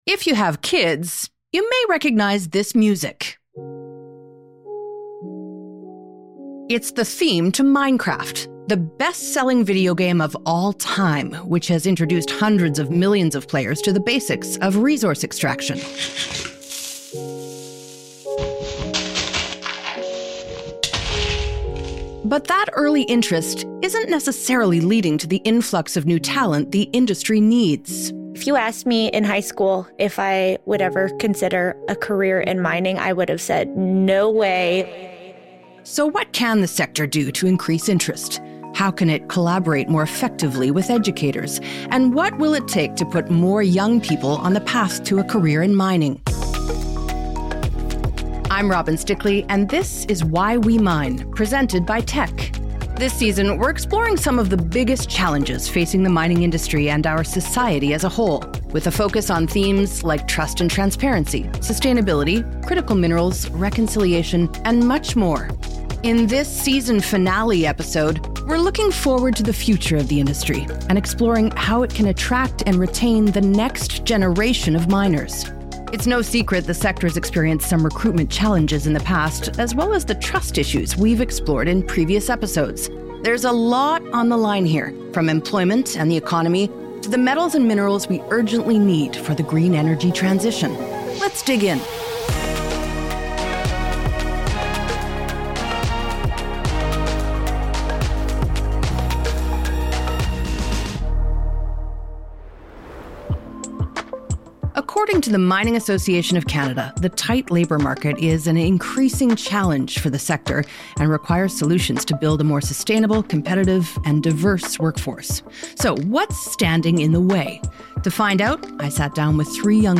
a frank conversation about the pros and cons of a career in modern mining. They also discuss how the appeal has grown in recent years as the industry evolves, both technologically and philosophically.